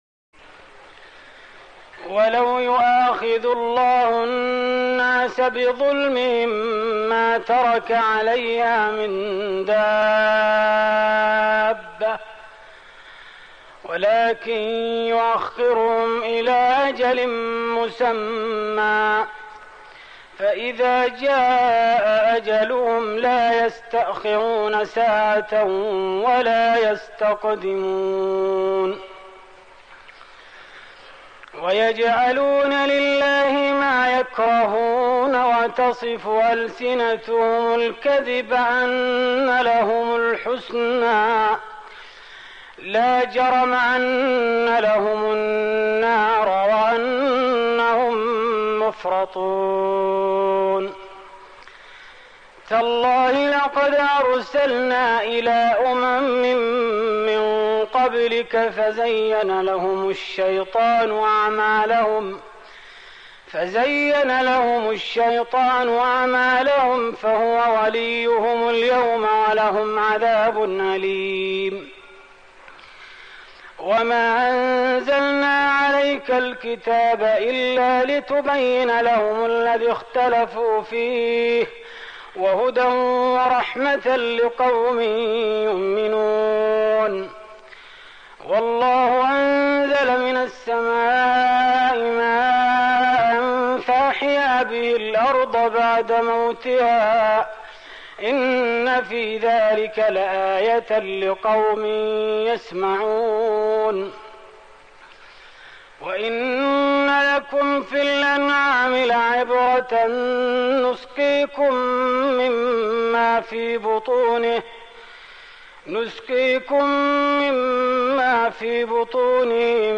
تهجد رمضان 1416هـ من سورة النحل (61-100) Tahajjud night Ramadan 1416H from Surah An-Nahl > تراويح الحرم النبوي عام 1416 🕌 > التراويح - تلاوات الحرمين